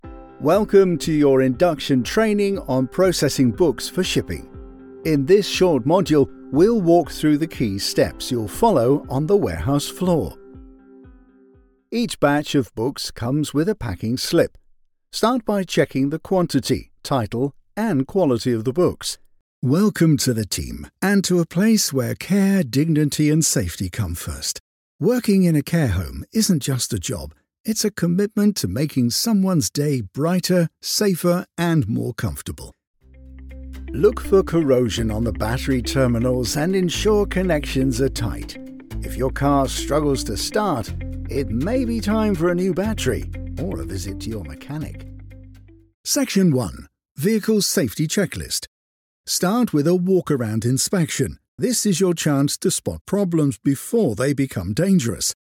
E-learning
I have a natural accent free (RP) style voice which is flexible and can adapt to most voiceover projects.
Rode NT1a Condensor Mic, Mac Mini m4, Adobe Audition CC, Scarlett Solo Interface, Tannoy 405 Studio monitors, Sennheiser HD 280 Pro monitoring headphones.
Deep